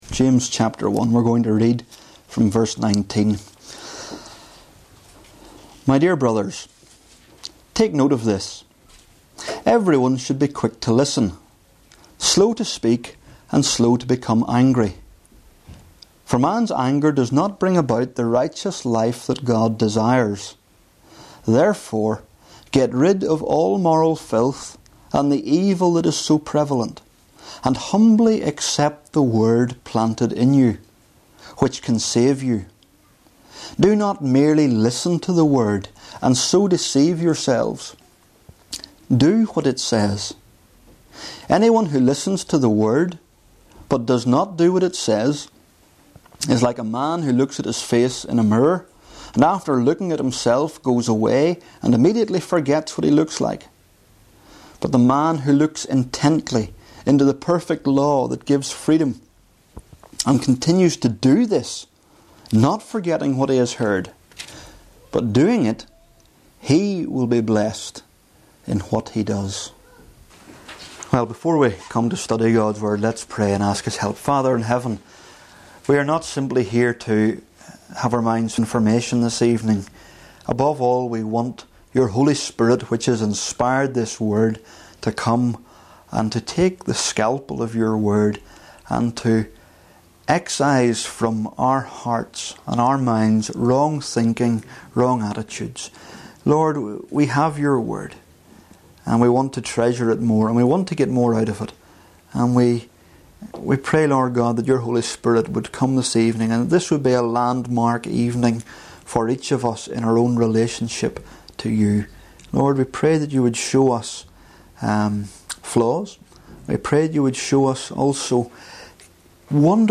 James | Single Sermons | new life fellowship